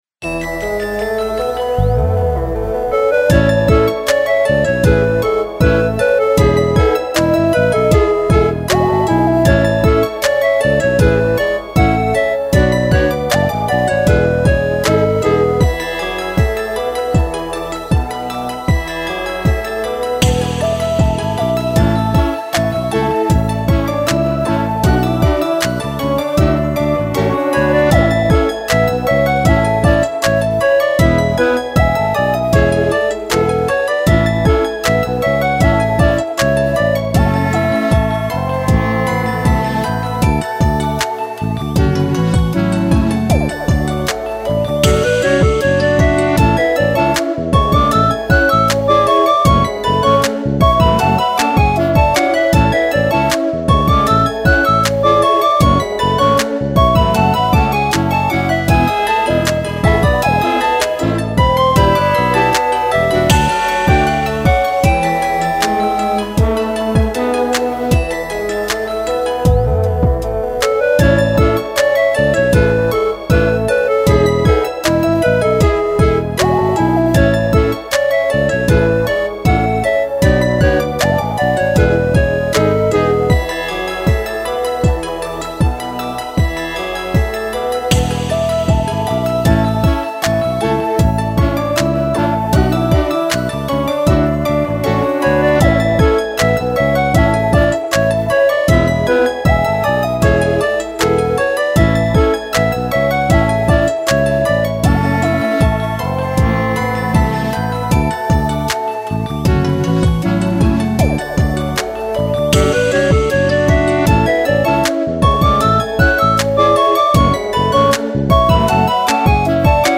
繊細で落ち着いたメロディーが特徴の、ローファイチックで癒される雰囲気のBGMです。
ゆったり癒され、どこか哀愁も漂うといったBGMがほしい場面でいかがでしょうか。